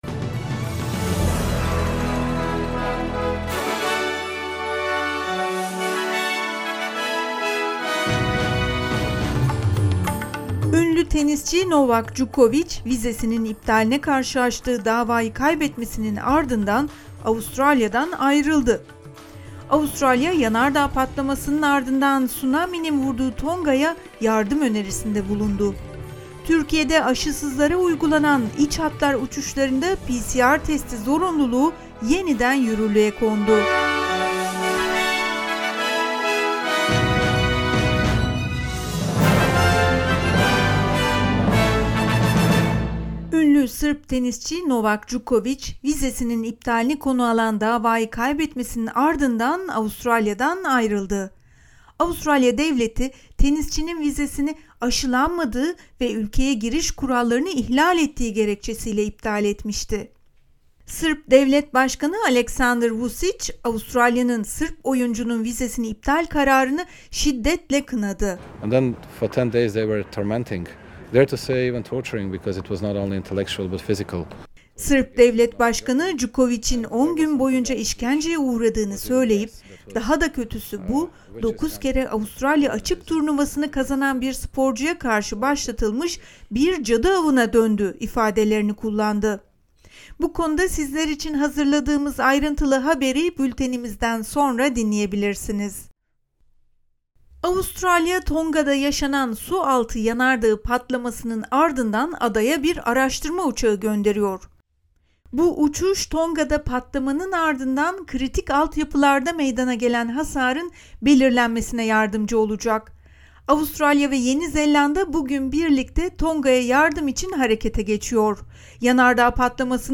SBS Türkçe Haber Bülteni 17 Ocak